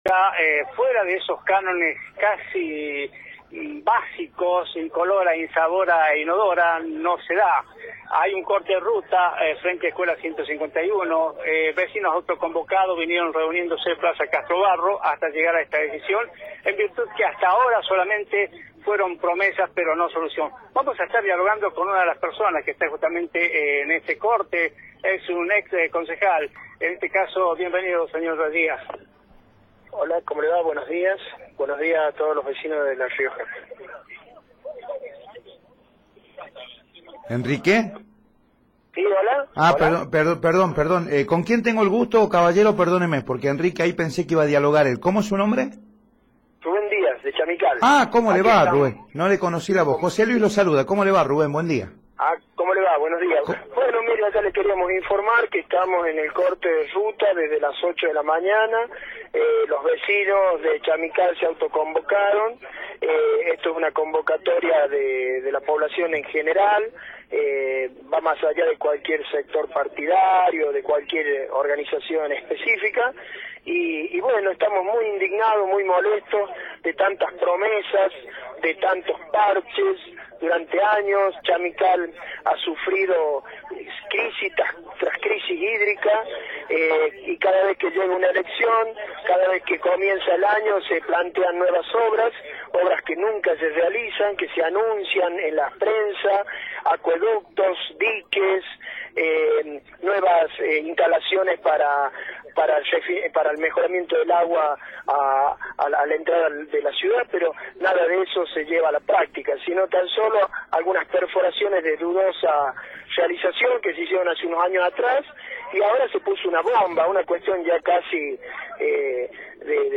Rubén Díaz, ex concejal de Chamical, por Radio Rioja
rubc3a9n-dc3adaz-ex-concejal-de-chamical-por-radio-rioja.mp3